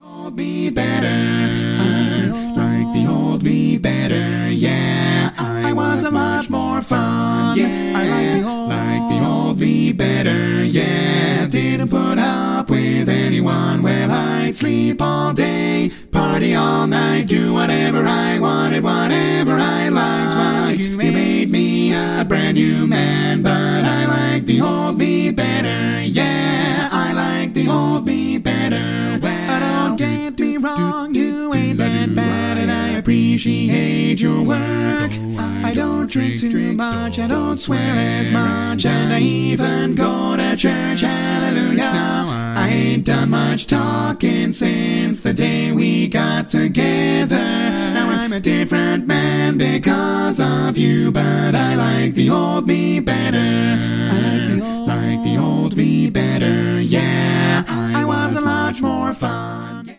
Category: Male